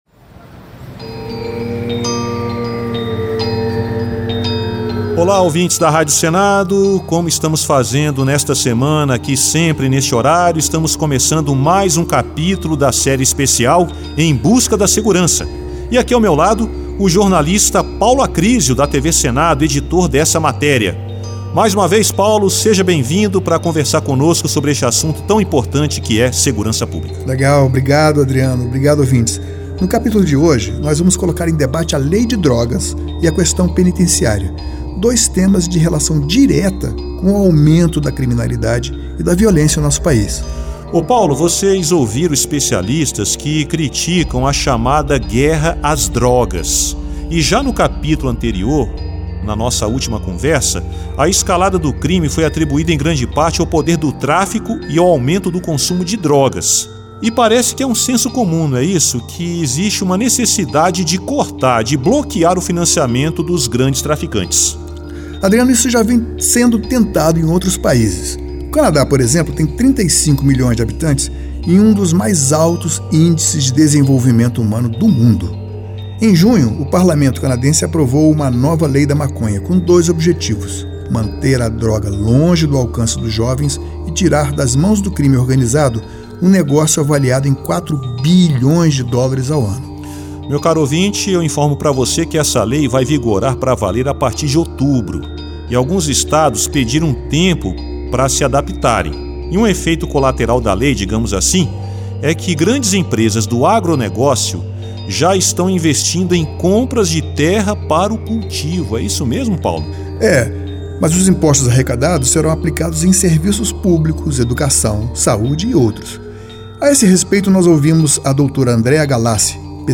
Segurança pública, saúde, educação, saneamento básico, economia e finanças, além das relações do Brasil com os demais países foram temas abordados nestas reportagens especiais intituladas de “Tela Brasil”.